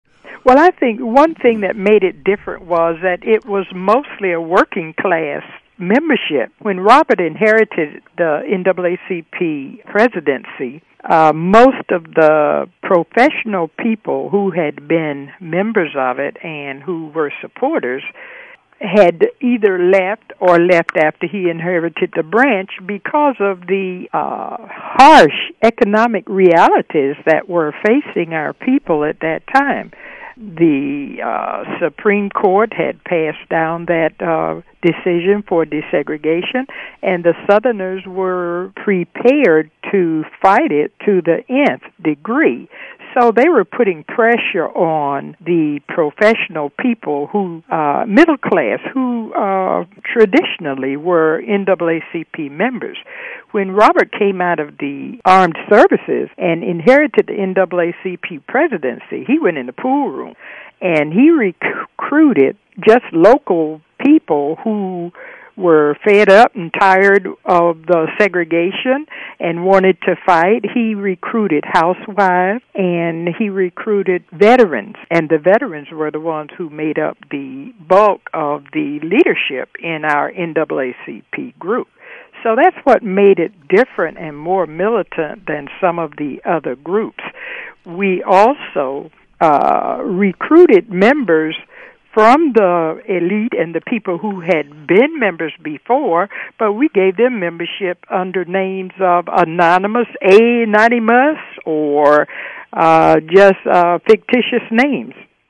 from an interview